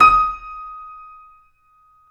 Index of /90_sSampleCDs/Roland L-CD701/KEY_YC7 Piano ff/KEY_ff YC7 Mono